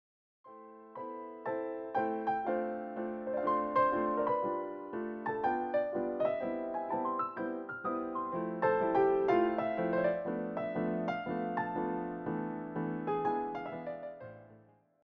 clear and expressive piano arrangements